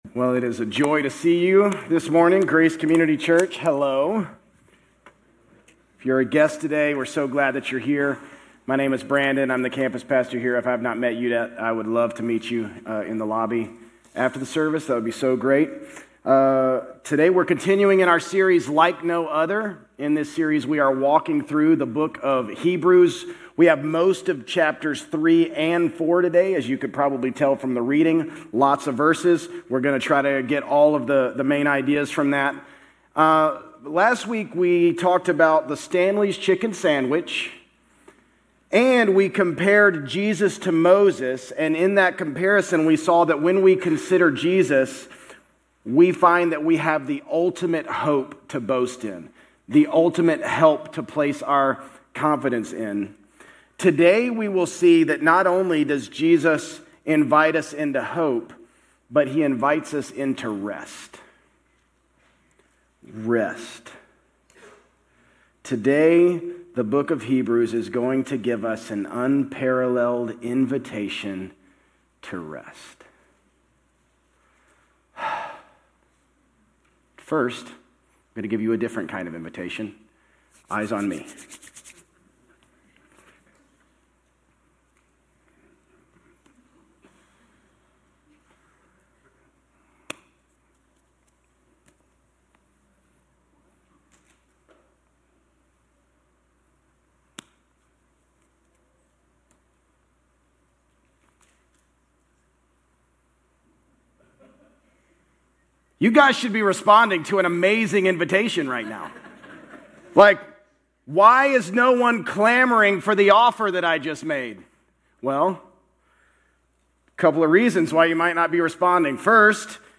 Grace Community Church Old Jacksonville Campus Sermons 10_12 Old Jacksonville Campus Oct 13 2025 | 00:37:36 1x 00:00 / 00:37:36 Subscribe Share RSS Feed Share Link Embed